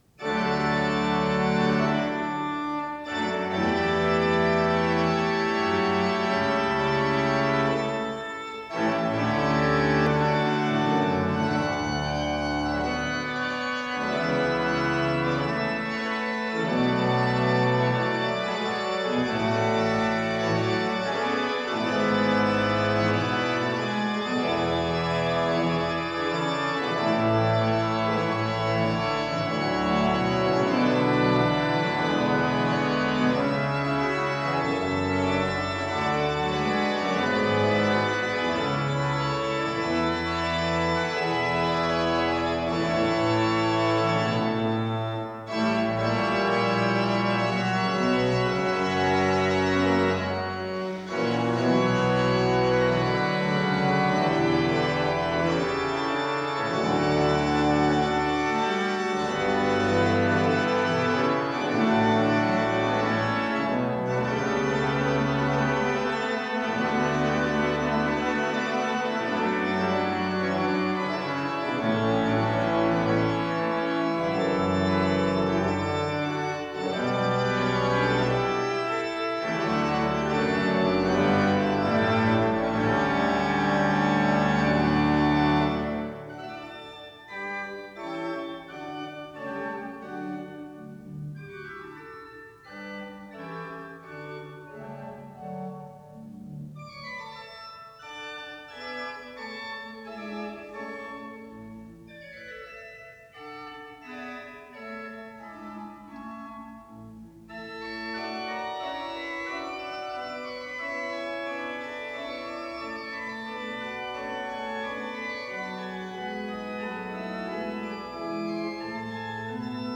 Index of /orgelkonsert/orgelaudio/BachJS
Bach Preludium och fuga Ess-dur.mp3